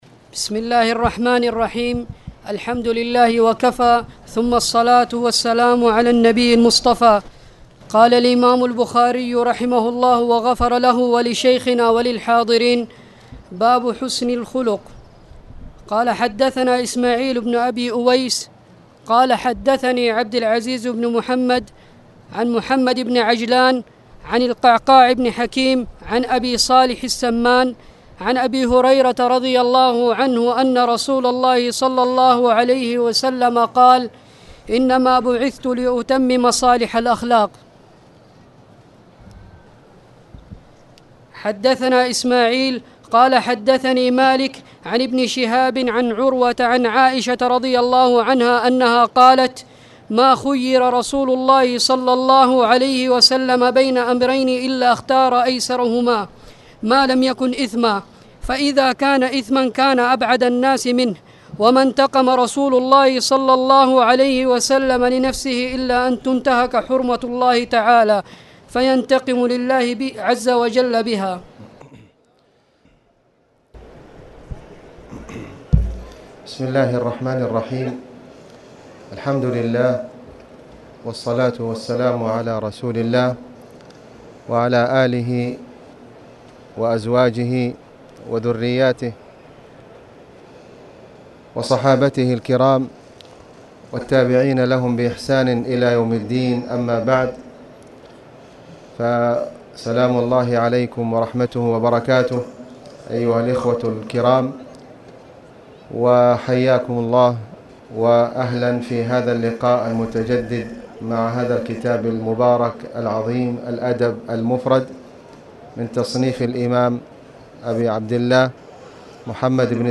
تاريخ النشر ١٤ جمادى الآخرة ١٤٣٨ هـ المكان: المسجد الحرام الشيخ: فضيلة الشيخ د. خالد بن علي الغامدي فضيلة الشيخ د. خالد بن علي الغامدي حسن الخلق